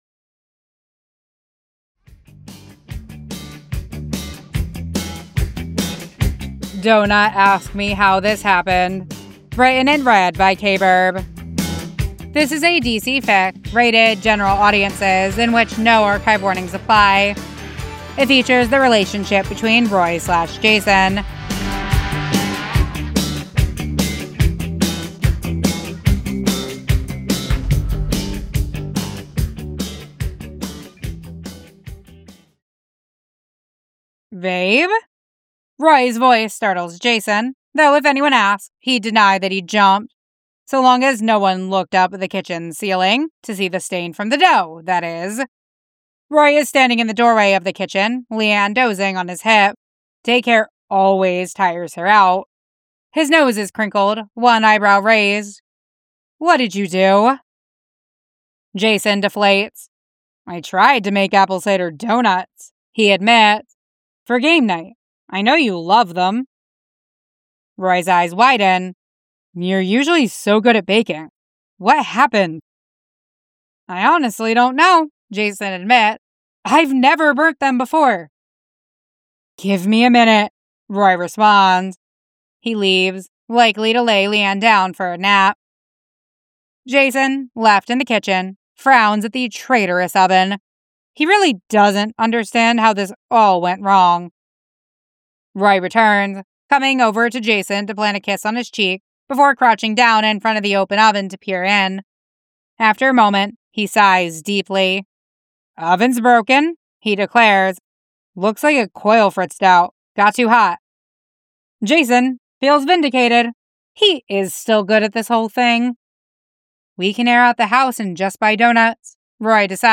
[Podfic of] Donut ask me how this happened